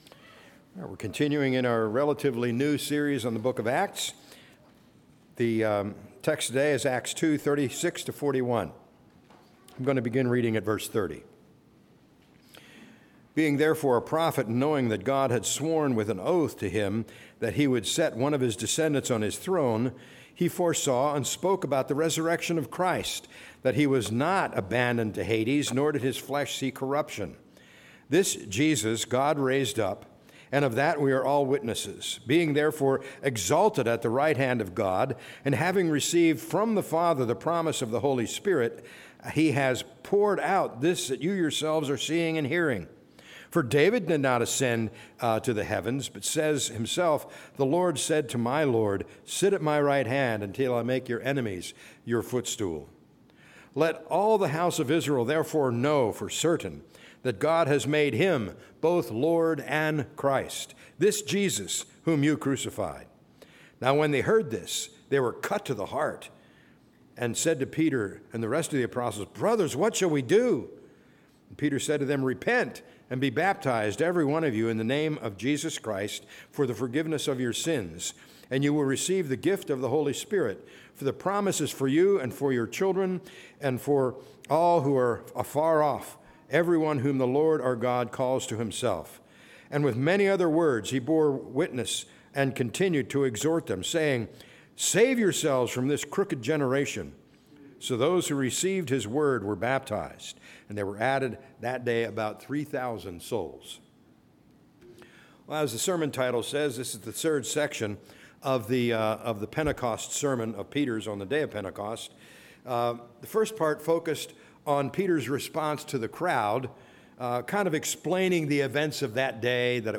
A message from the series "Acts 2025/26."